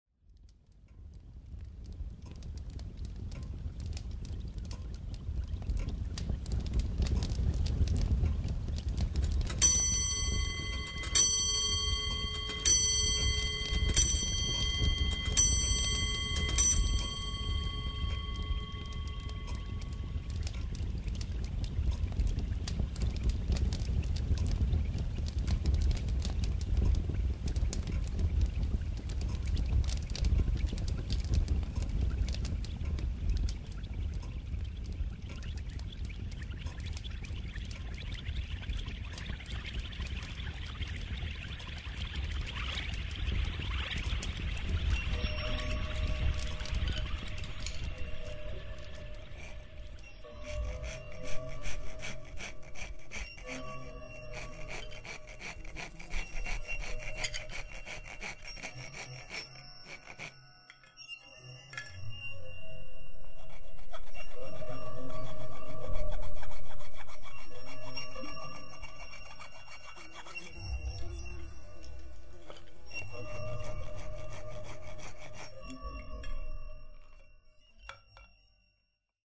Les sons sont mixés, spatialisés et assemblés comme les mots d’un poème sonore, puis diffusés dans un logis en ruine en Ardèche.
Jouant sur le contraste de sonorités naturelles simples (travail de la pierre, du bois…) et électroniques (souffle, effets de matière…), l’installation doit créer un univers reconstituant la vie et le travail des femmes, des hommes et des enfants qui vécurent dans ces murs.